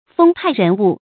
風派人物 注音： ㄈㄥ ㄆㄞˋ ㄖㄣˊ ㄨˋ 讀音讀法： 意思解釋： 指善于迅速改變自己立場或觀點的人 出處典故： 張潔《沉重的翅膀》：「他們又知不知道他是個見風使舵的 風派人物 ？」